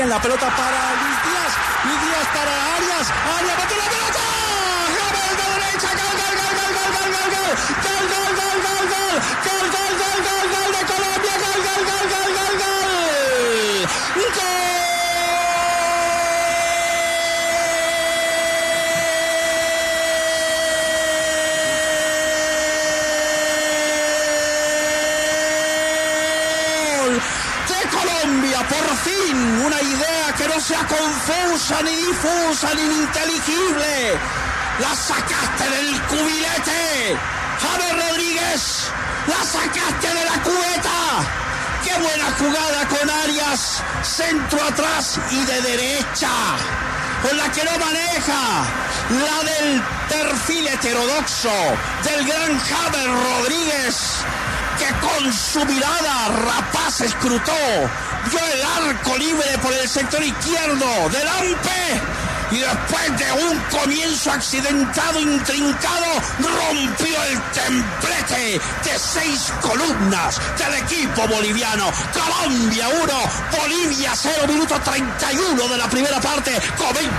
Martín De Francisco narró con todo el sentimiento el golazo de James Rodríguez.